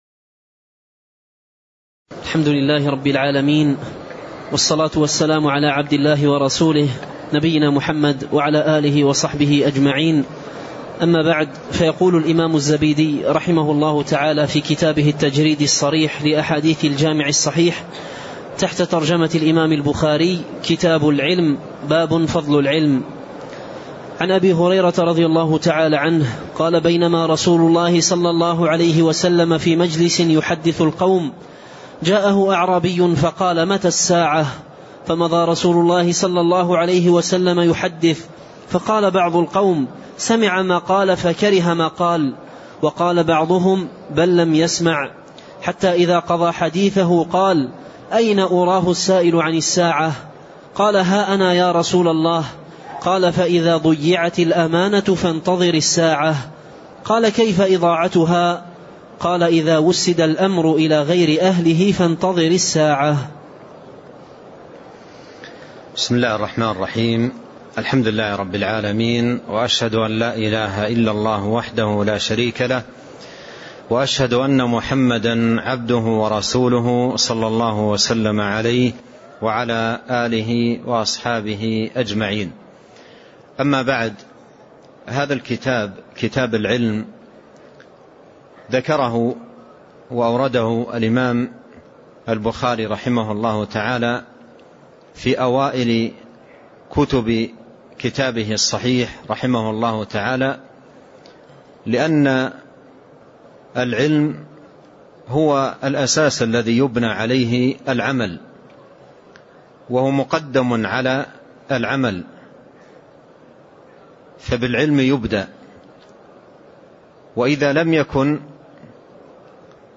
تاريخ النشر ٢٦ ربيع الثاني ١٤٣٣ هـ المكان: المسجد النبوي الشيخ